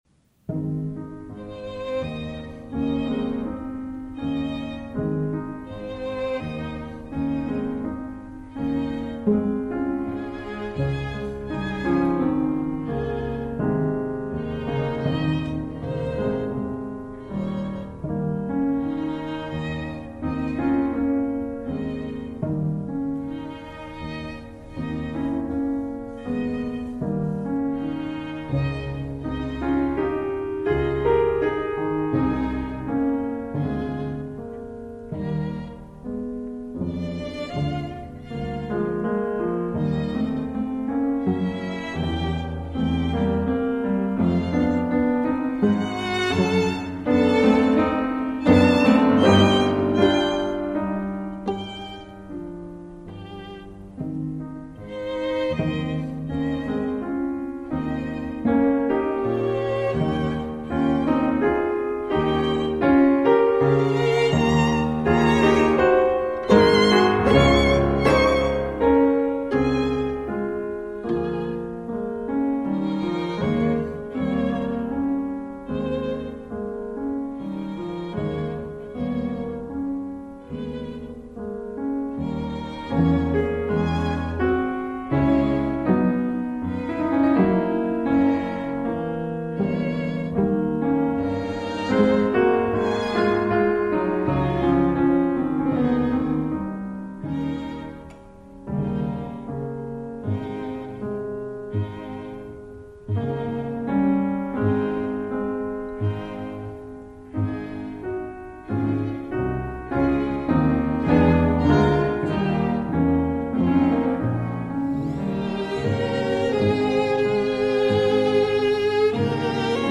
para piano y cuerdas.
Andante un poco Adagio. 2.6 MB